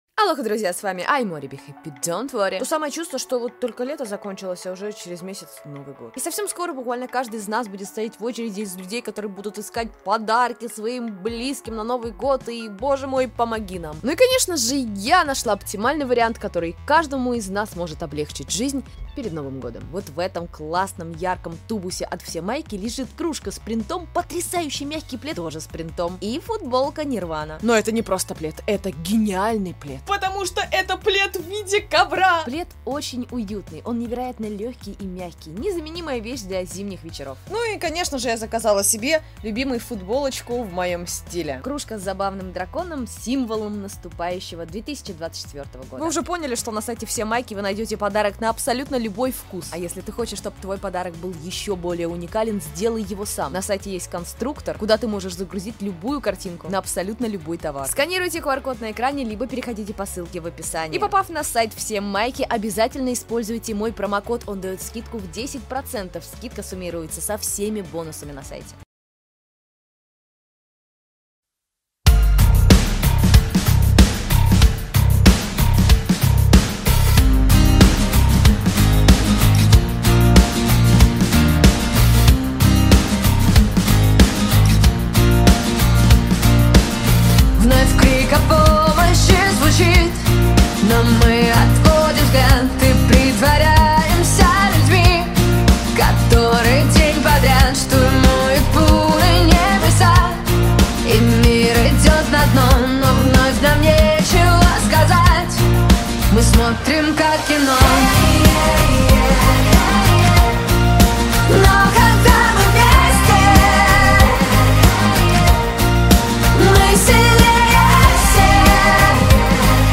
1:09 - Кавер